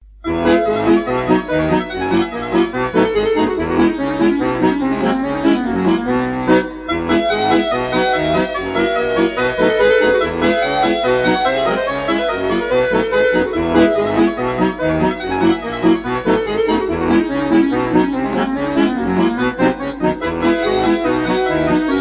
Музыка созданная с помощью гармони